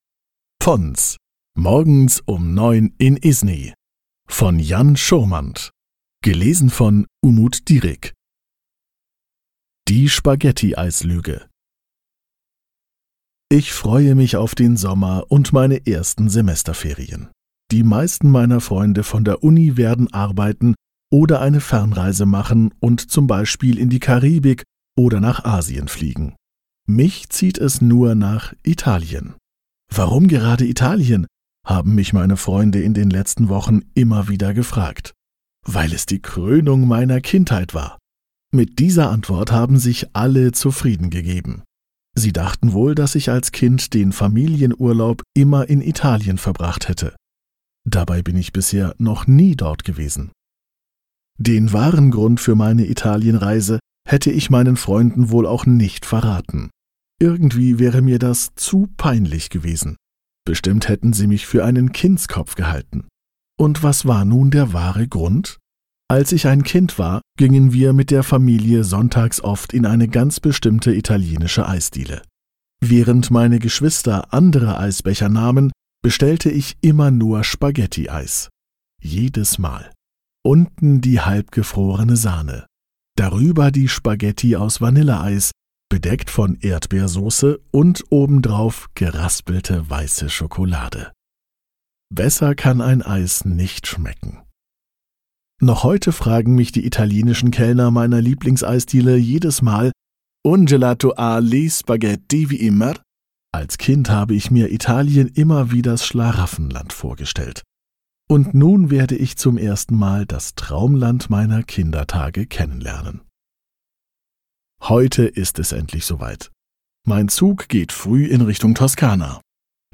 PONS Hörbuch Deutsch als Fremdsprache